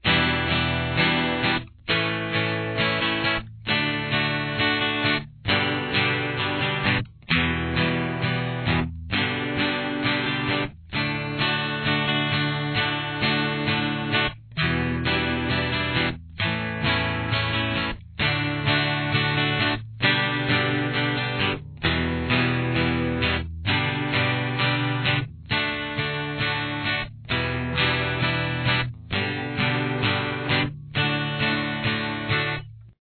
• Key Of: D
Guitar Solo 2
Guitar 1: (chords)